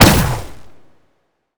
sci-fi_weapon_plasma_pistol_02.wav